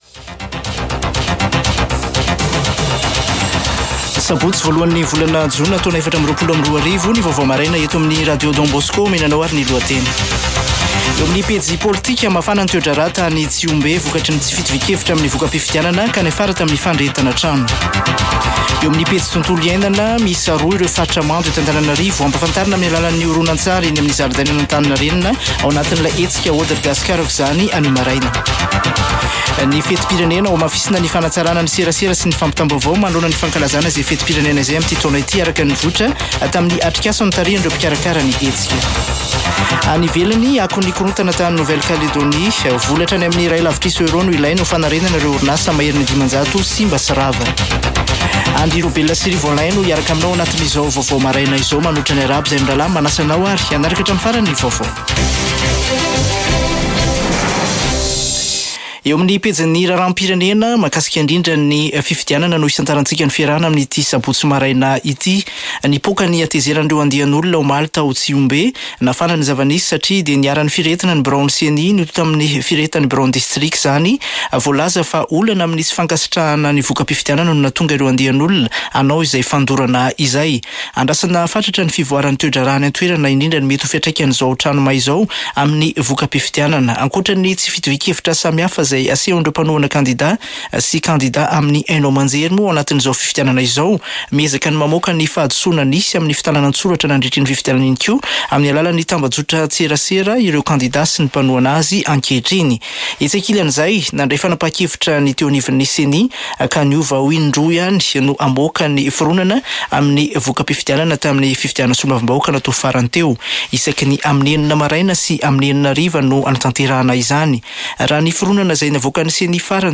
[Vaovao maraina] Sabotsy 01 jona 2024